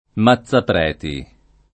Mazzapreti [ ma ZZ apr $ ti ] cogn.